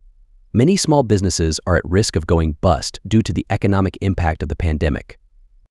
Play.ht-Many-small-businesses-are-at-risk-of.wav